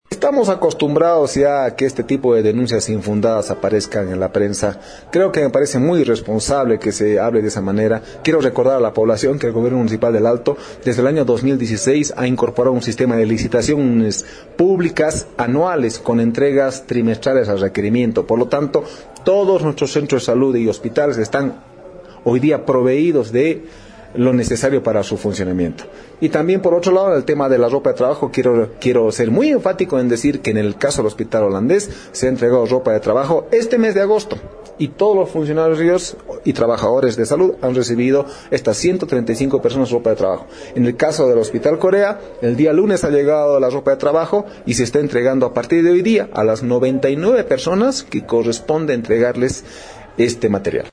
(El Alto, 29 ago).- El secretario Municipal de Salud y Deportes, Vladimir Ameller, en conferencia de prensa descalificó de injustificado el paro de 48 horas anunciado por los trabajadores en Salud, la autoridad manifestó que se cumplió con el sector con la dotación de la ropa de trabajo y que los hospitales y centros de salud esta abastecidos de medicamentos e insumos.
El-secretario-Municipal-de-Salud-y-Deportes-Vladimir-Ameller.mp3